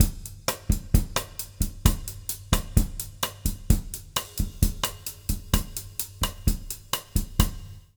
130BOSSA03-L.wav